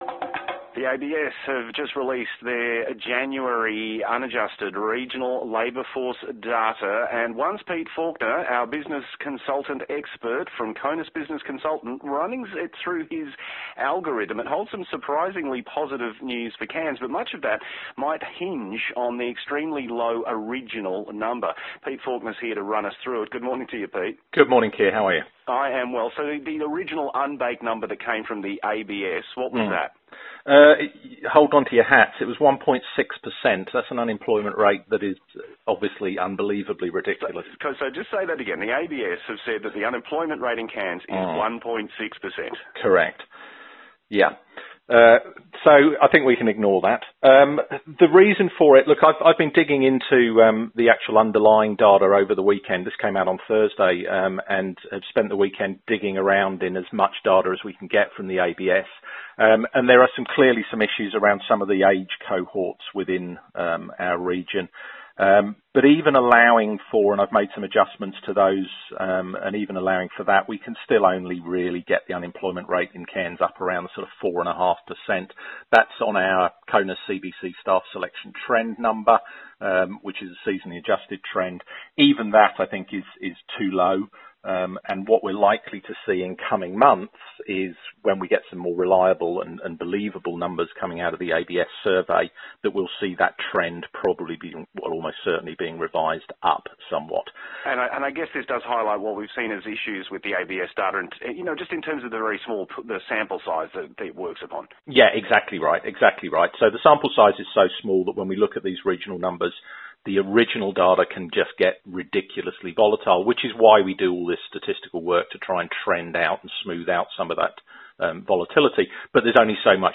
Talking Cairns Labour Force Trend data on ABC Far North this morning